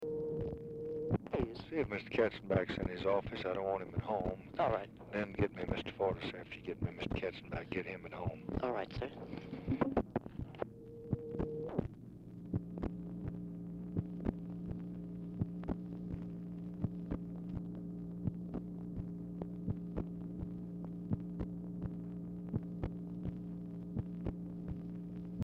Telephone conversation # 5439, sound recording, LBJ and TELEPHONE OPERATOR, 9/2/1964, time unknown | Discover LBJ
Telephone conversation
RECORDING STARTS AFTER CONVERSATION HAS BEGUN
Dictation belt